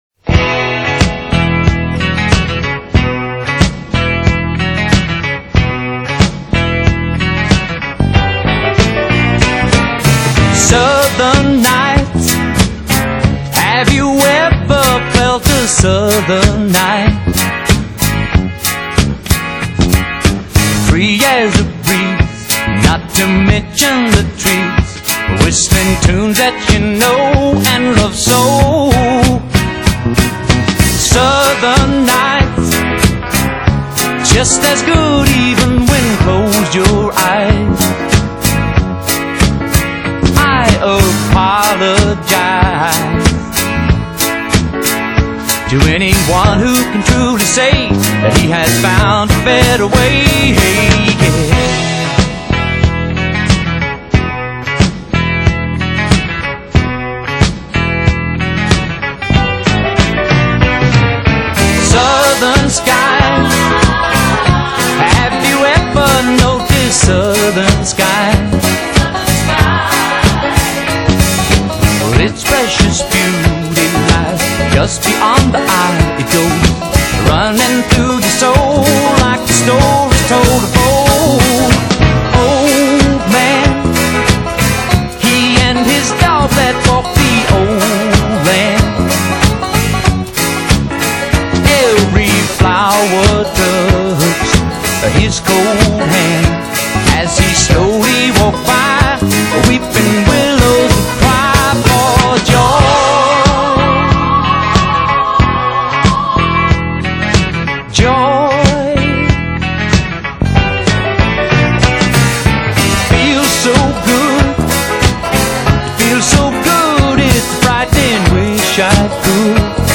Genre:Country